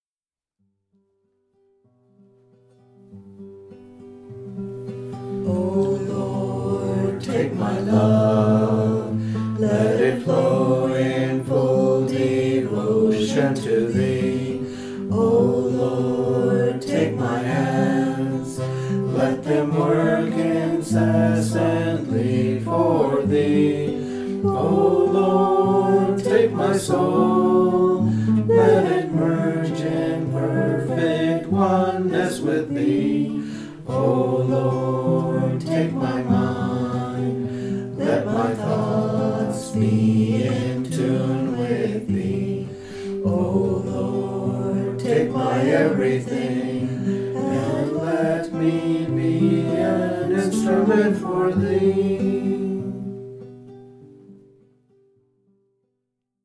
1. Devotional Songs
Major (Shankarabharanam / Bilawal)
8 Beat / Keherwa / Adi
Medium Slow
* Western notes are in the Key of C